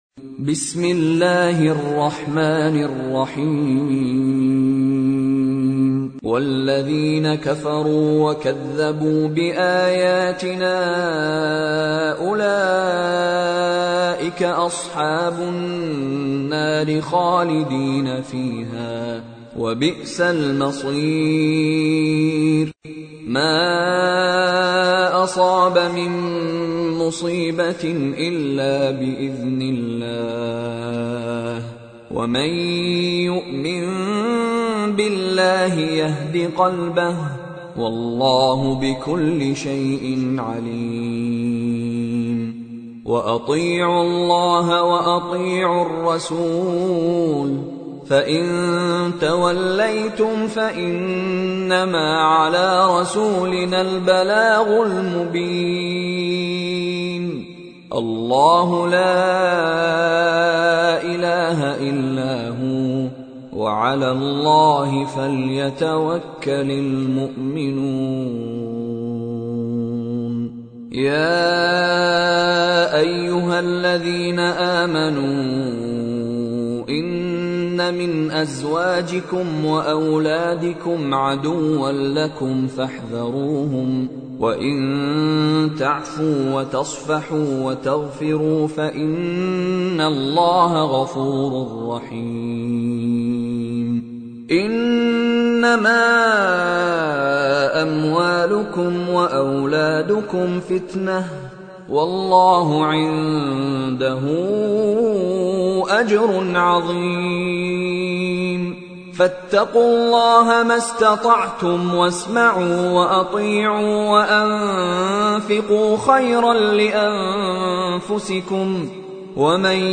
پخش آنلاین و دانلود قرائت آیات هفته چهارم تیر ماه ، قرآن پایه ششم ، با قرائت زیبا و روحانی استاد مشاری رشید العفاسی